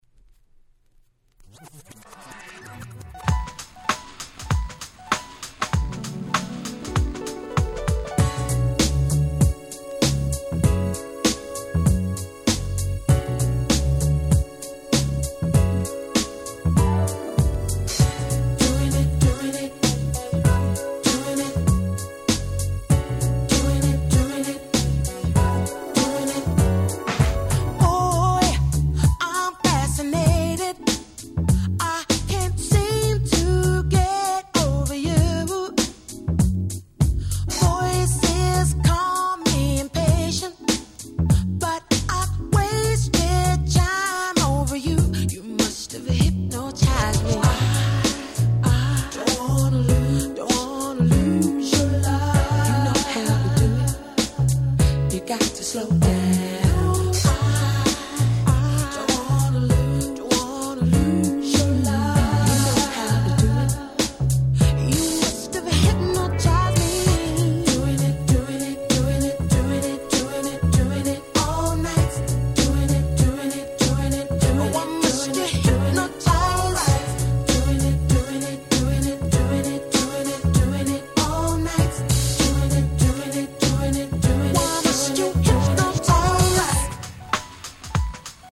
97' Nice UK R&B !!